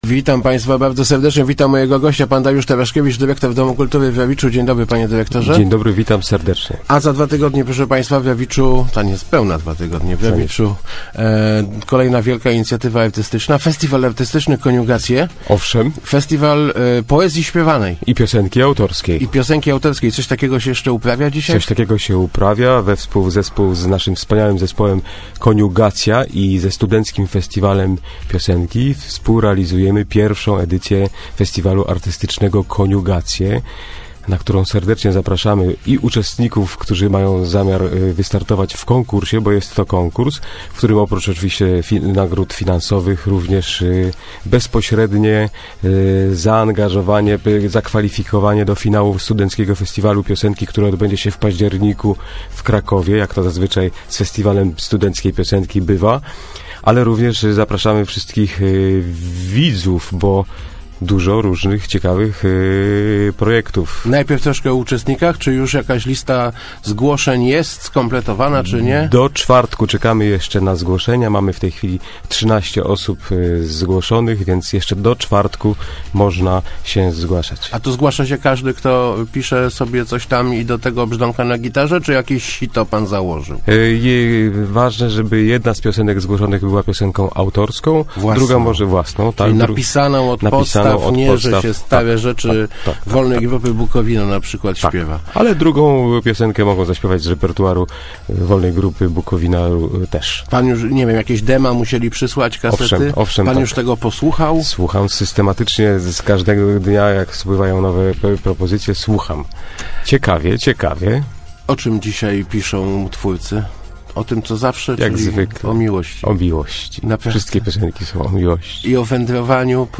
27.03.2017. Radio Elka